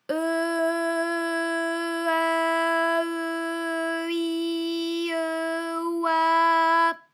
ALYS-DB-001-FRA - First, previously private, UTAU French vocal library of ALYS
e_e_eu_e_i_e_oi.wav